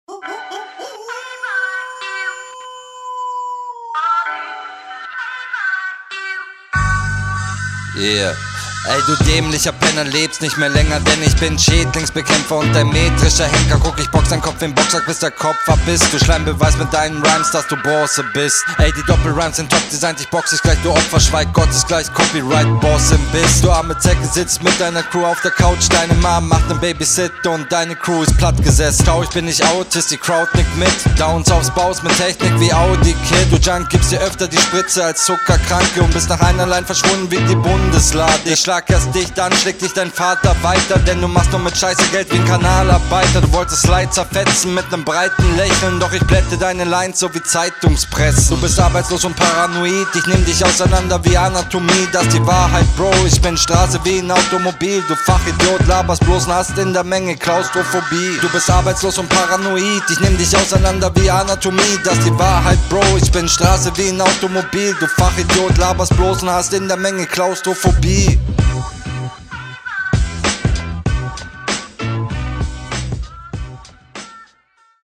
Flow ist schon ok so, aber was laberst du haha Viele Zweckreime und gefühlt (oder …